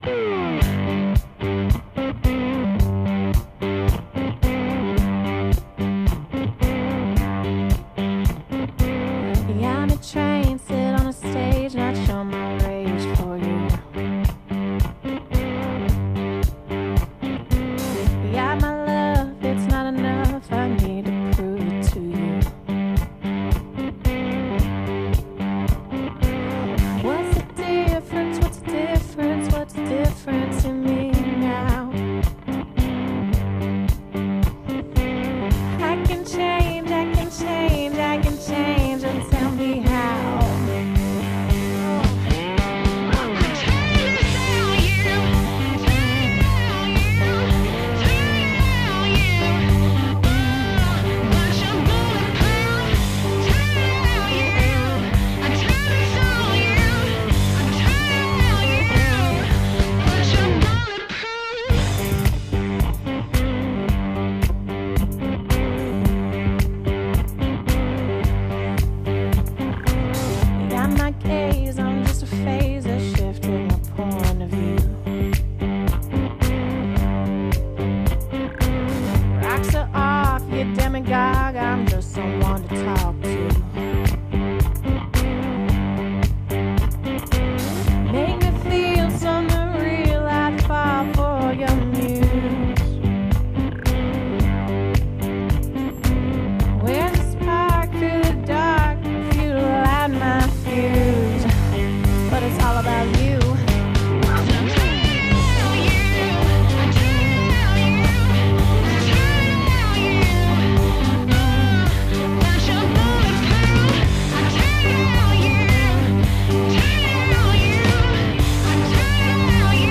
Blues en las ondas 602.ogg